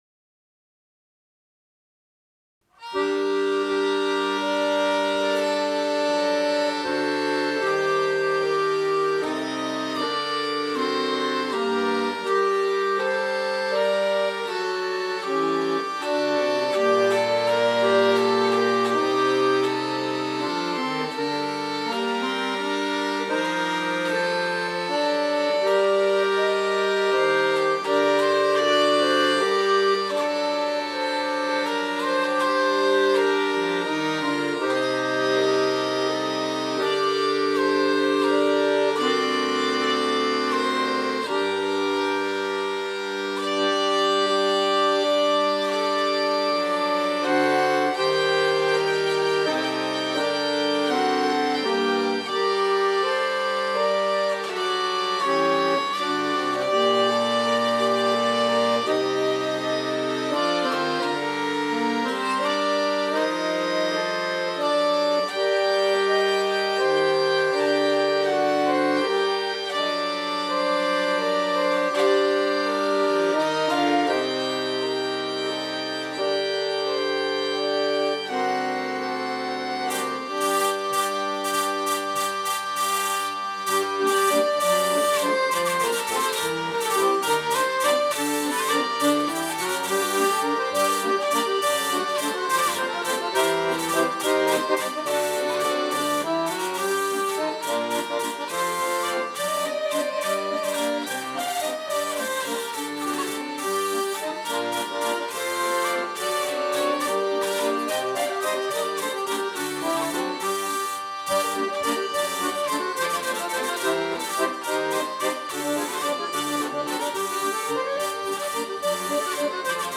air d’église de la fin du XIXème siècle
accordéon
vielle